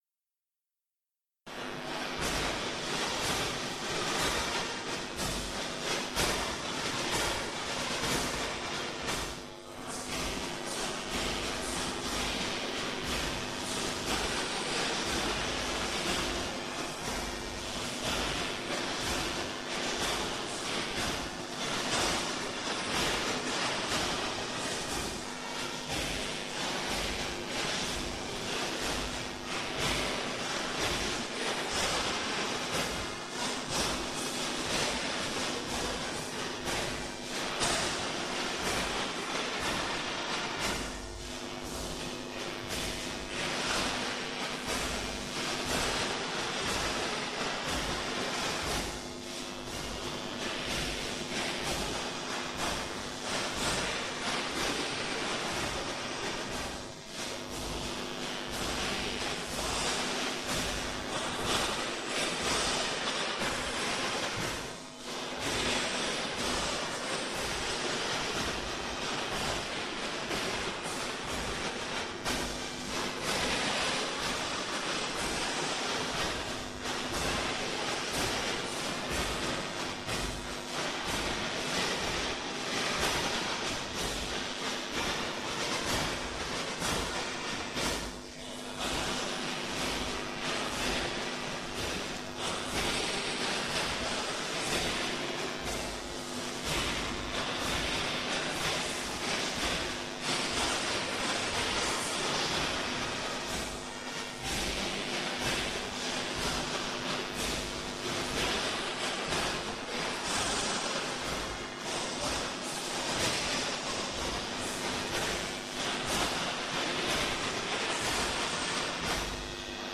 Mit seinen warmen Melodien und liebevollen Worten beschreibt es die Verbundenheit zu unserem Ort, zu seiner Landschaft und zu den Menschen, die ihn prägen.
Aufgenommen und bearbeitet wurde sie mit den damals verfügbaren technischen Möglichkeiten.